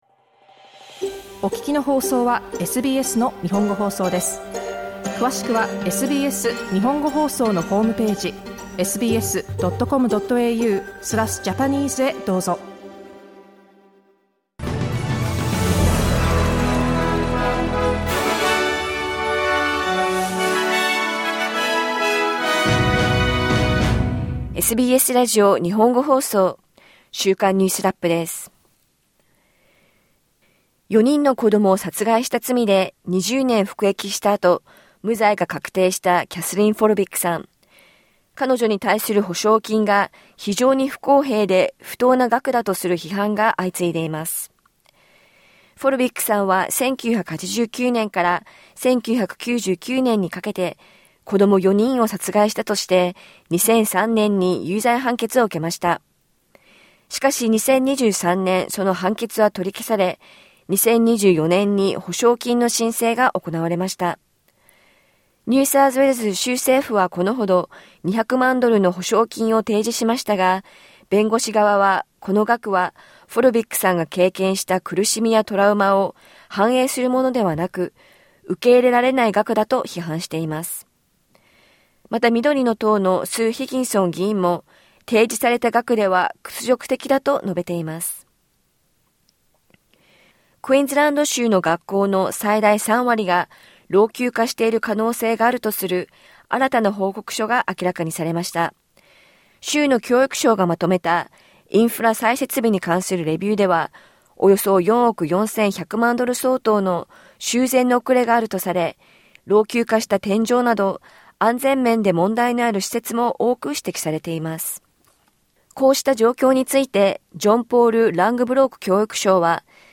SBS日本語放送週間ニュースラップ 8月8日
クイーンズランド州の学校の最大3割が老朽化している可能性があるとする新しい報告書が発表されました。連邦政府が2035年の温室効果ガス削減目標の策定を進める中、気候変動対策の活動家たちは「少なくとも60パーセント以上」の削減を求めています。1週間を振り返るニュースラップです。